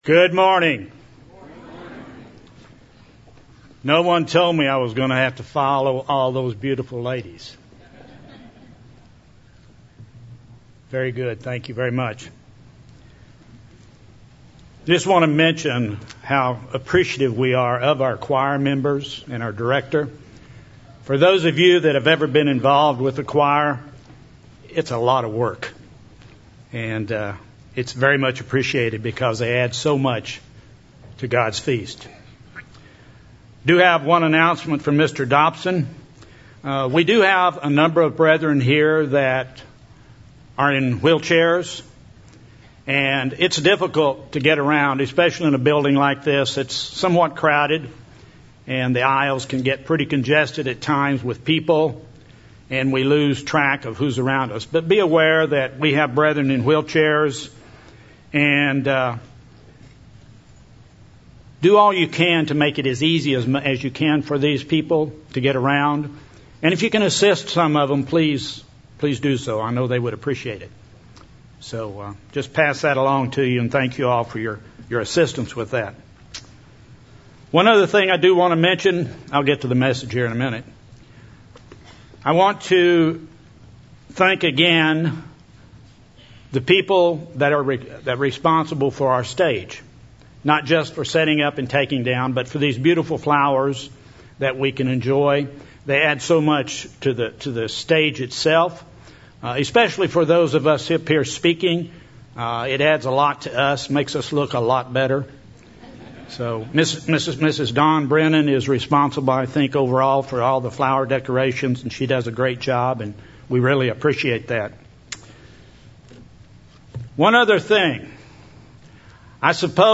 This sermon was given at the Branson, Missouri 2018 Feast site.